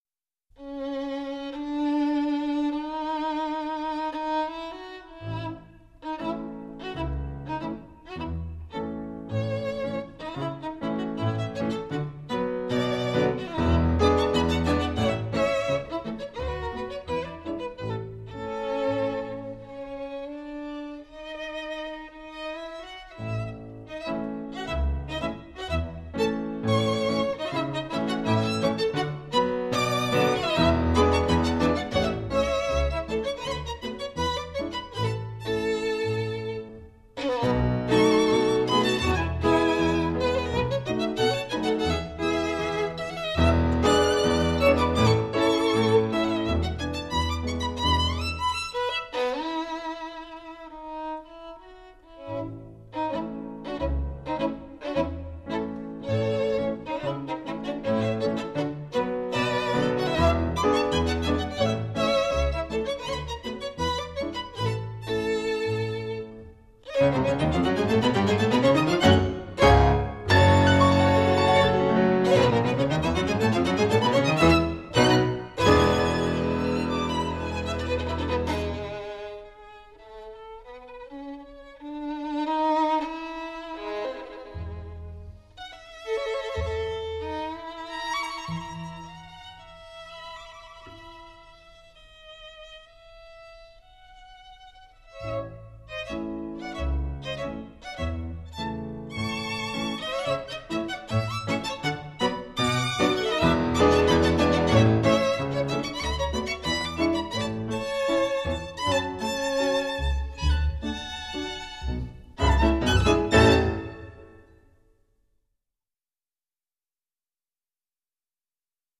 古典沙龙
钢琴手、小提琴手、大提琴手、低音大提琴手和女高音都受过严 格的古典音乐训练，技艺扎实，配合默契。
整辑音乐节奏清晰明快，舞蹈 感极强。